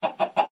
sounds / mob / chicken